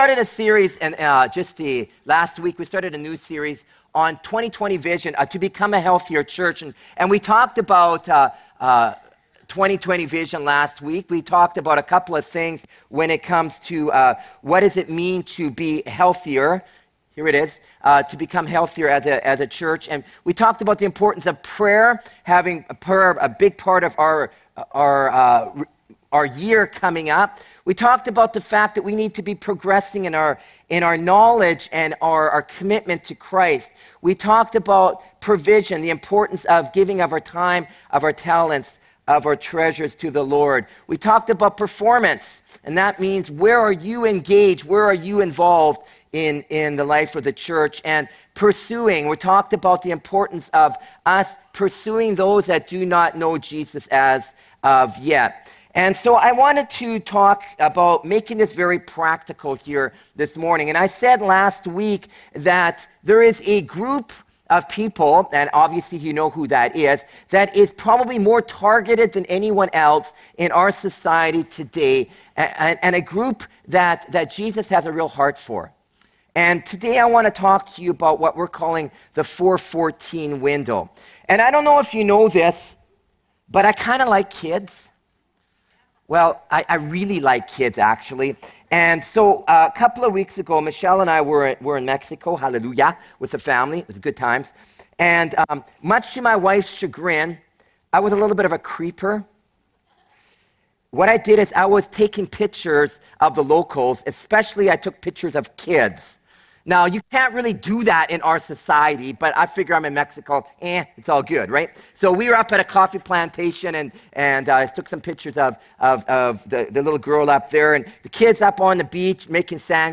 Sermons | Surrey Pentecostal Assembly